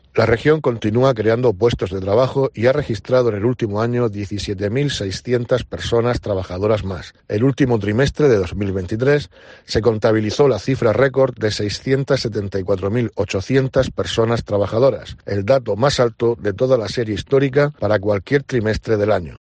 Víctor Marín, consejero de Empleo